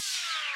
Aquainted Fall FX.wav